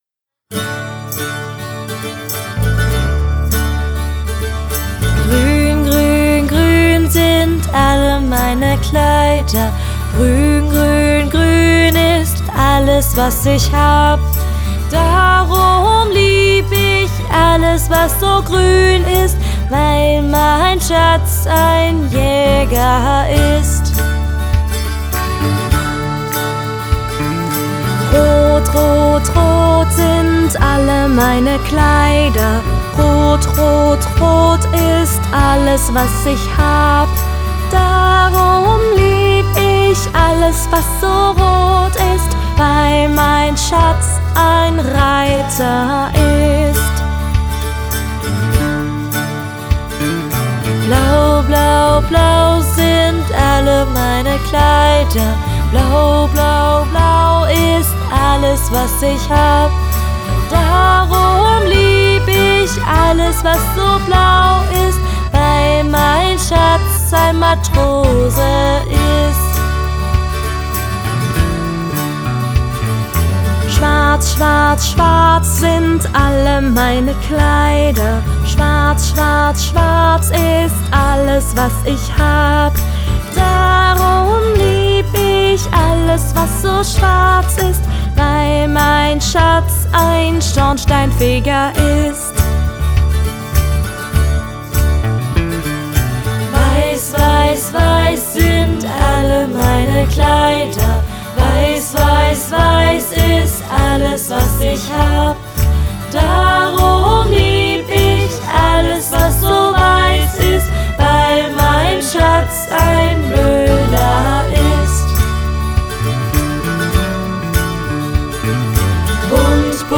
Traditionelle Lieder